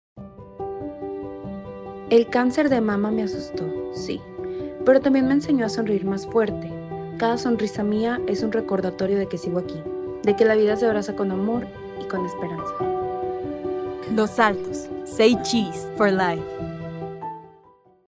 Historias reales de mujeres reales
Los nombres y voces utilizados son ficticios, con el objetivo de transmitir mensajes universales de prevención y esperanza.
testimonial-5-v2.mp3